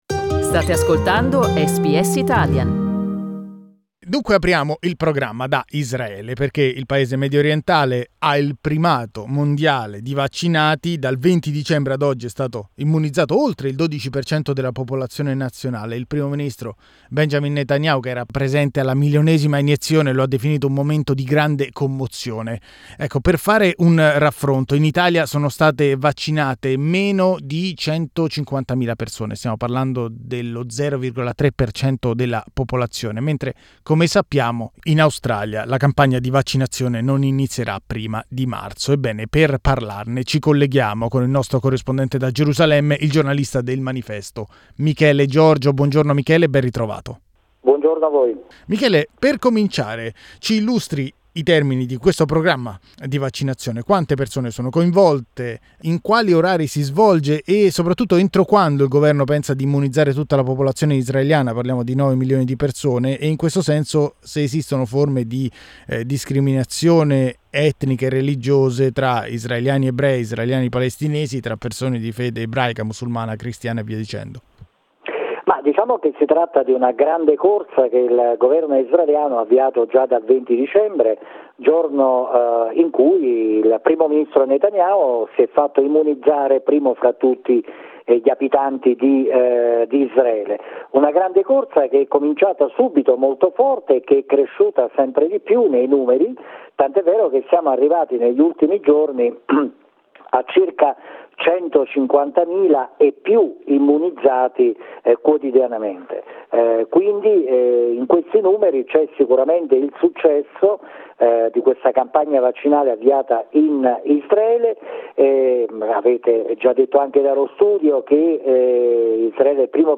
Riascolta qui l'analisi del giornalista